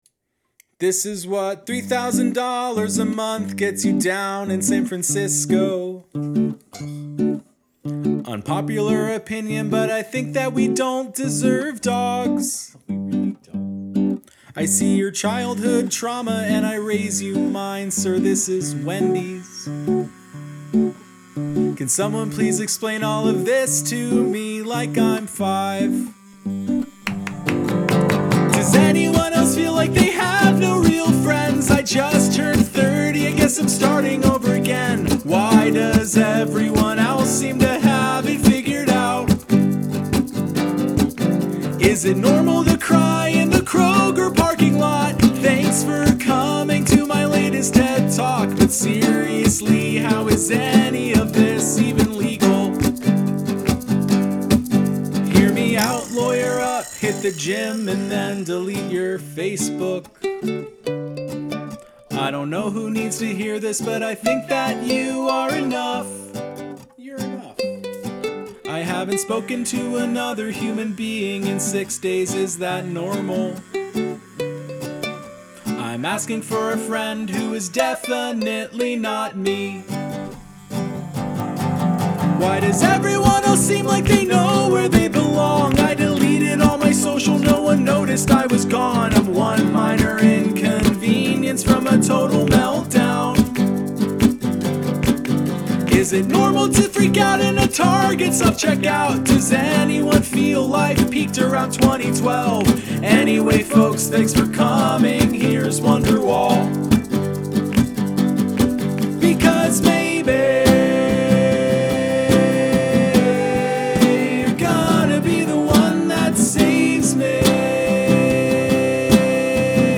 This is so catchy and energetic with banger lines!
Musically it's great; I love those vocal harmonies!
Loved the sonic differences b/w the respective sections of your song.
The classic tumultuous lyrics with an upbeat instrumental are a classic, and I loved how you diversified the percussion.